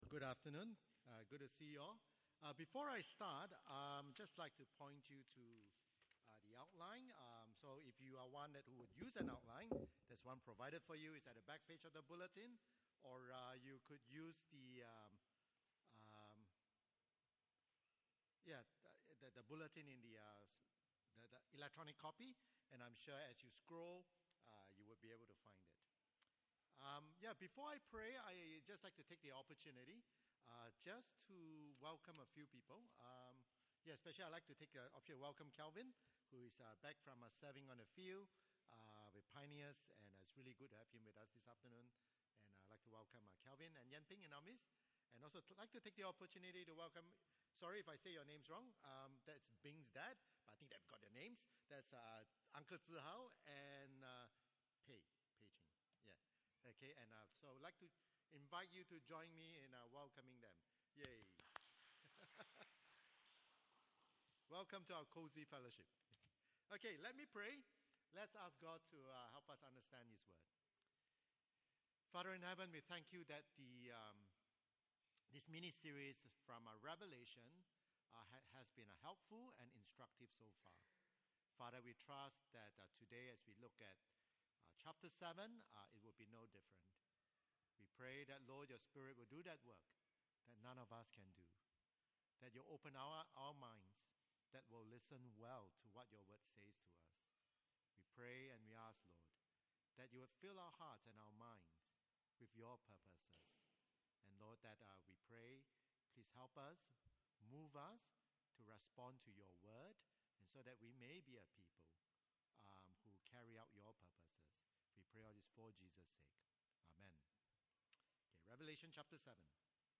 Service Type: 2 PM